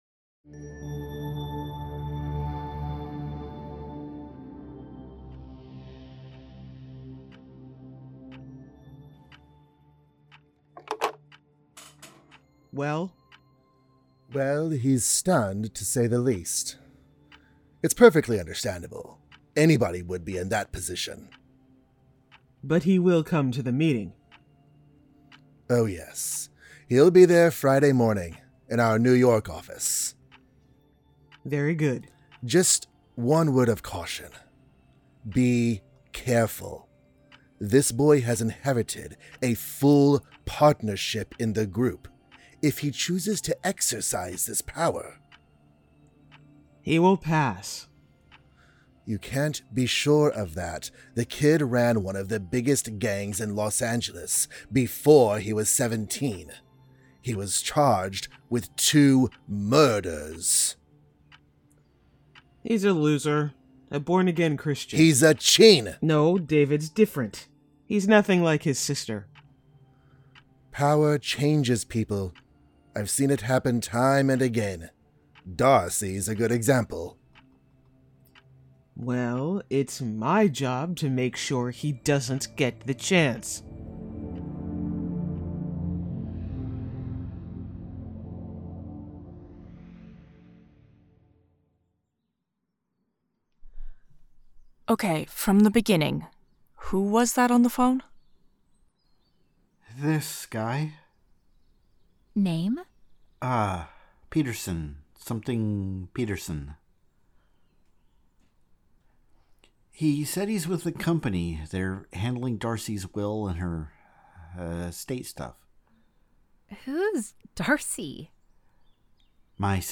The Ocadecagonagon Theater Group
strangers-in-paradise-the-audio-drama-book-7-episode-14